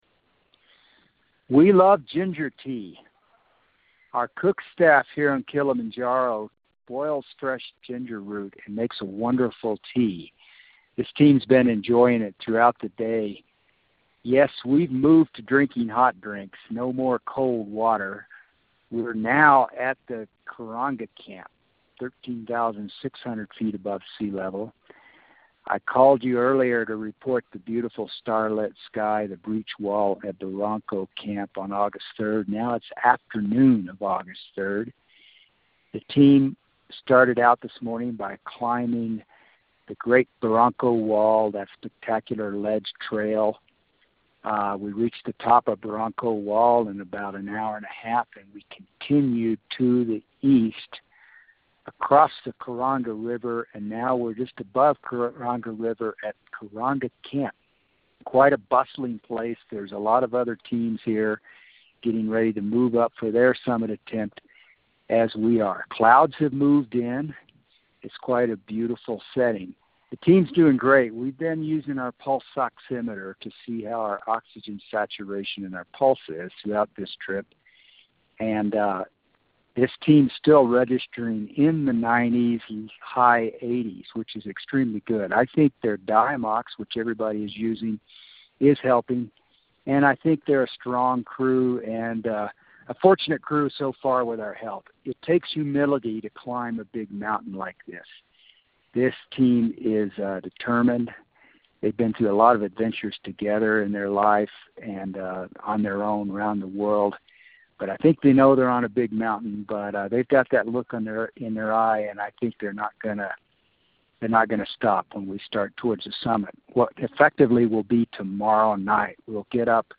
August 3, 2016 – Team at Karanga Camp - 13,600ft above Sea Level